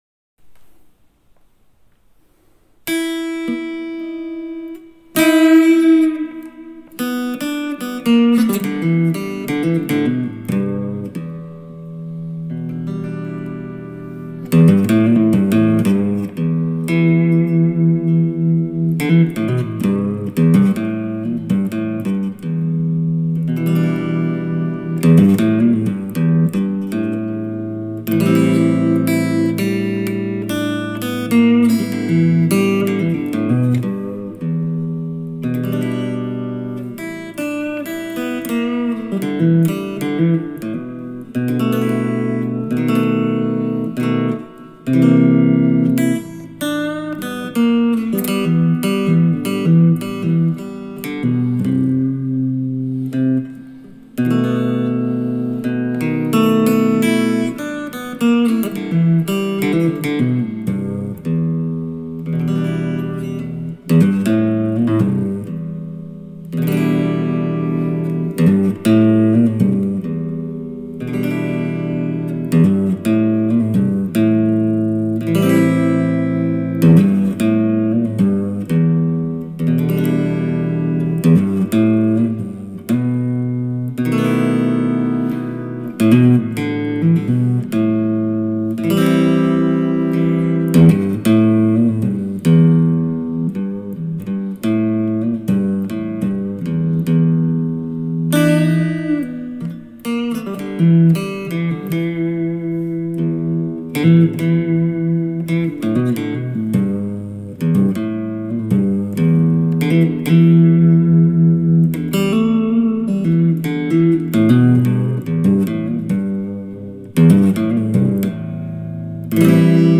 Ich hab ja nichts Besonderes zu bieten, nehme derzeit nur mit meinem Handy auf. Aber bitte: kleiner Blues alone in e. Nix von Bedeutung. your_browser_is_not_able_to_play_this_audio Was ich meine sind besonders die Töne am Anfang und am Ende.